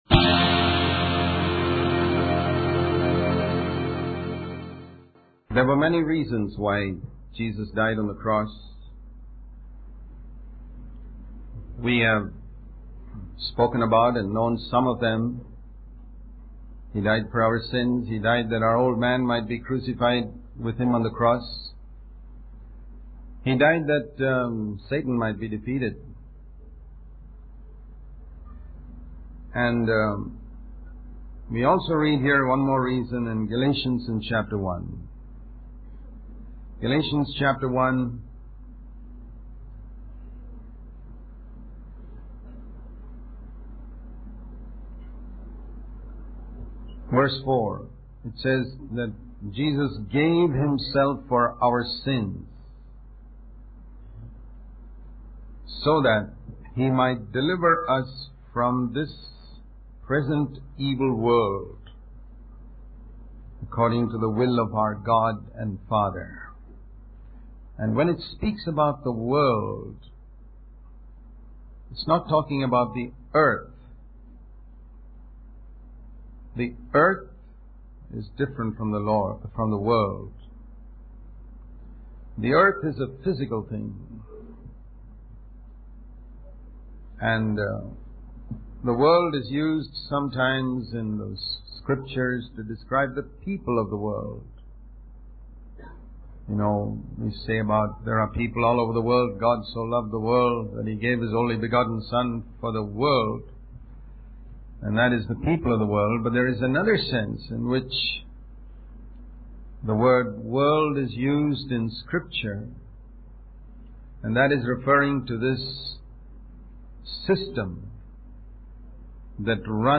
In this sermon, the speaker emphasizes the ongoing battle against the world system that surrounds us. He compares it to plugging holes in a leaky roof, where new holes keep appearing.